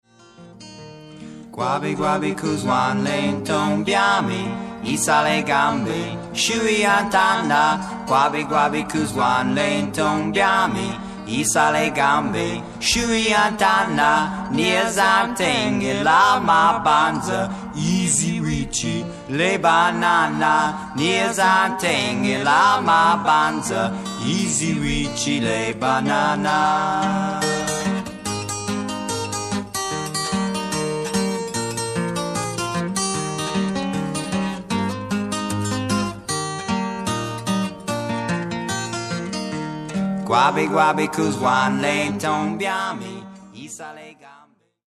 60年代の「コーヒーハウス」文化を匂わす温もりのある録音が聴き所。
ハーモニカ